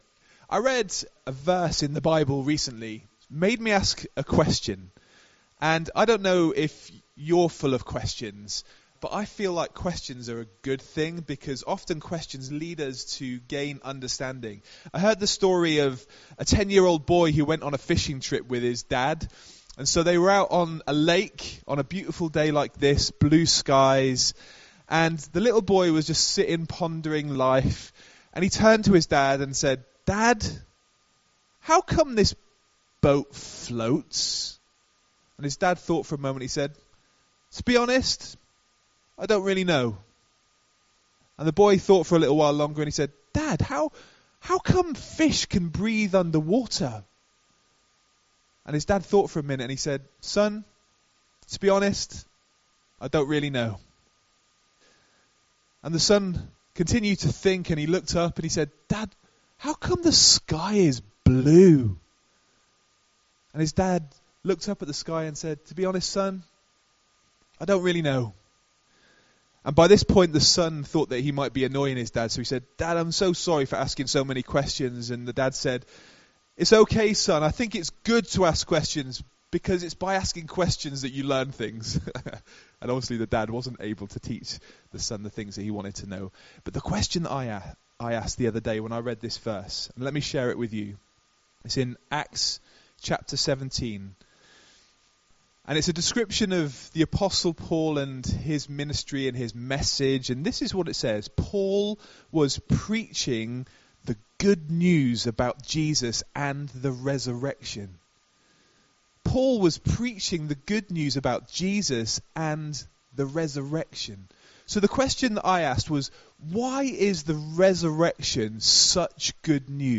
Notes In a short but sweet Easter Sunday sermon